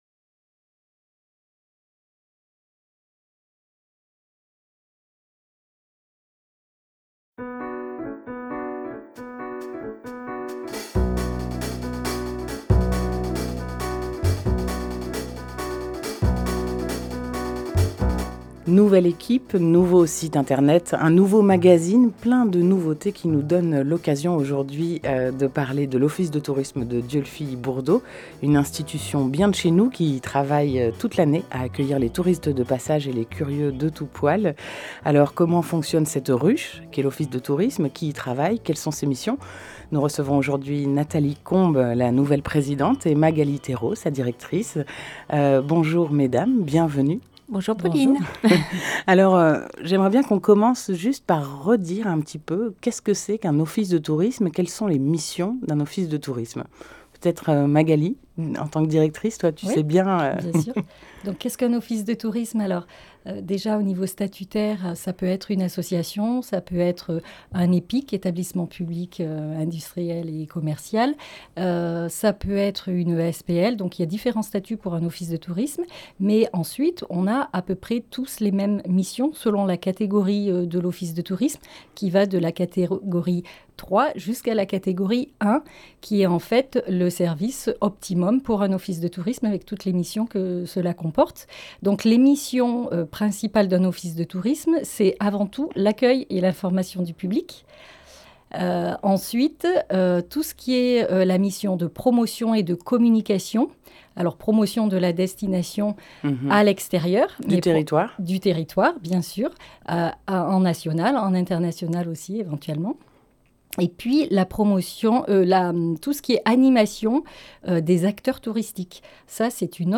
22 avril 2019 19:36 | Interview